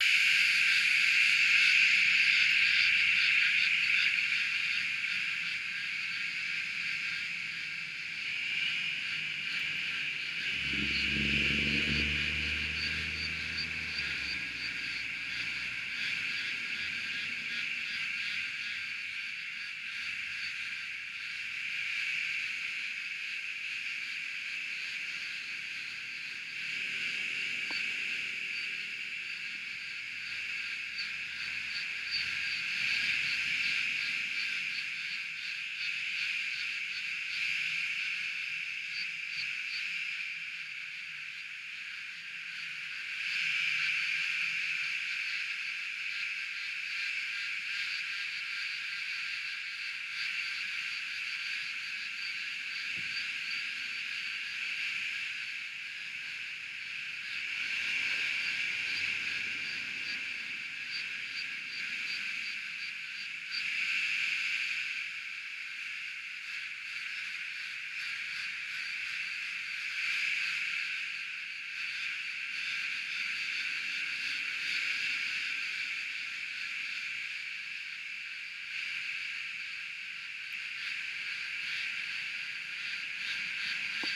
Early summer days in this part of Australia ring with sound of cicadas:
It’s the males that call – the females are silent but respond to a male by flicking their wings. For such a small critter the noise level they produce is incredible – over 100dB from around a metre away from just one insect.
The only conclusion possible is that there there are two quite different species calling at the same time, most likely a bigger one in the lower frequency and a smaller one in the higher.
cicadas.mp3